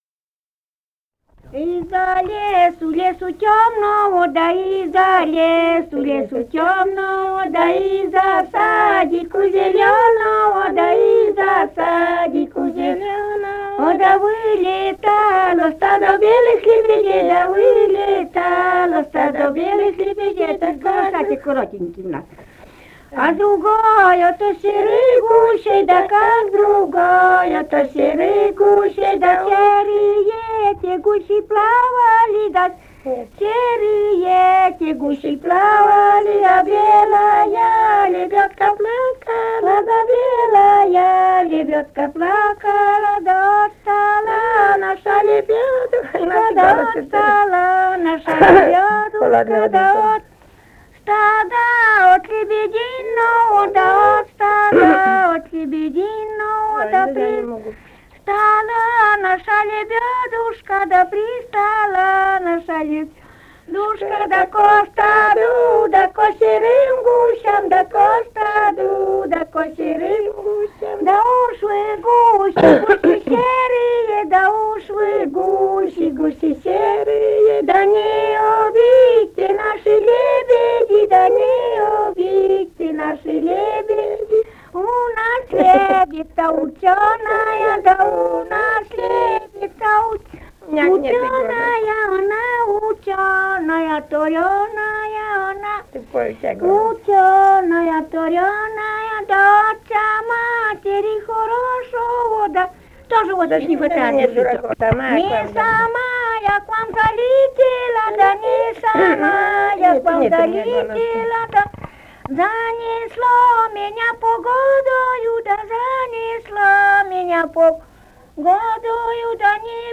Живые голоса прошлого 148. «Из-за лесу, лесу тёмного» (свадебная).